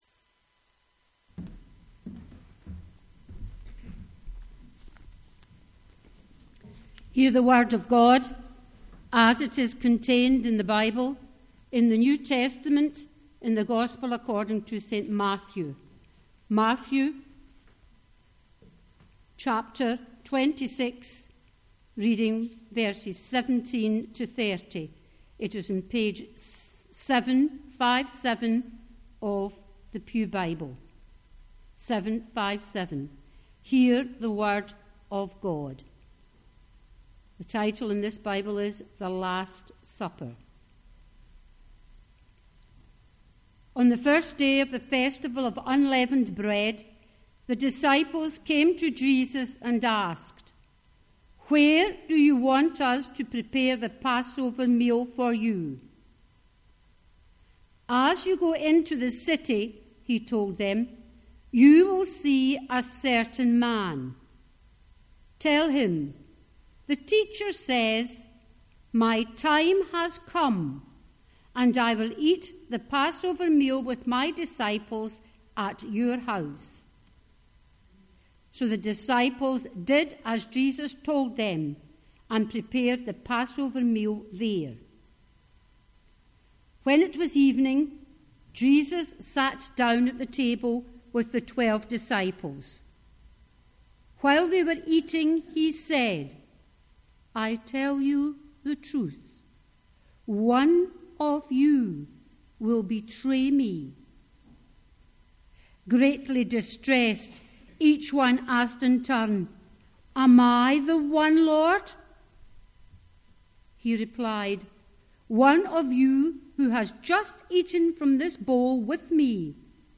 The Scripture Reading prior to the Sermon is Matthew 26: 17-30